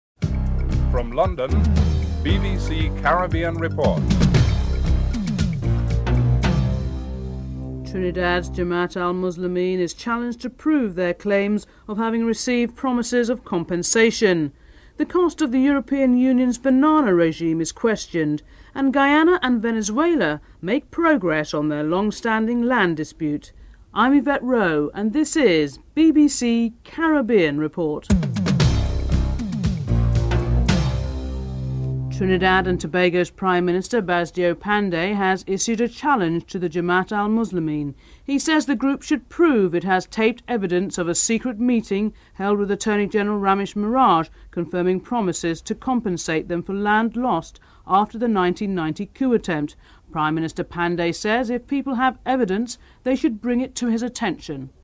1. Headlines (00:00-00:31)
2. Trinidad and Tobago Jamaat al Muslimeen is challenged to prove their claims of having received promises of compensation. Prime Minister Basdeo Panday and Muslim Leader Yasim Abu Bakr are interviewed (00:32-03:50)